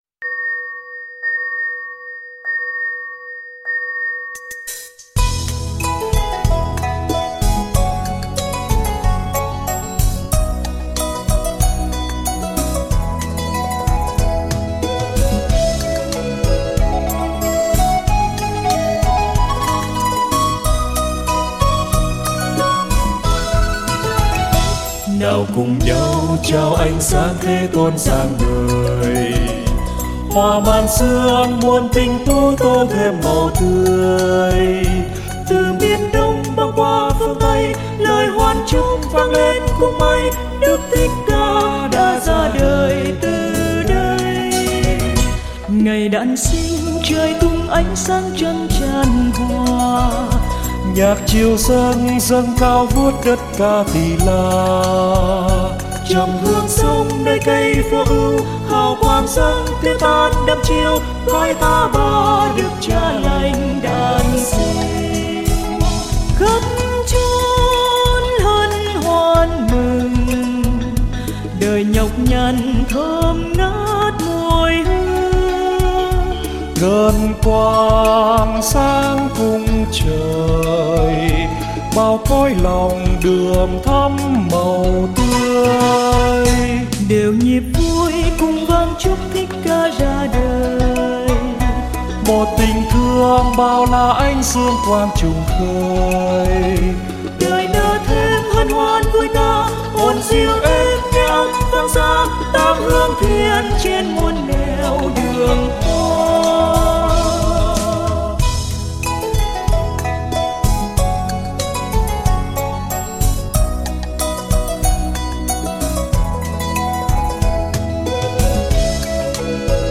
Ca đoàn A Dật Đa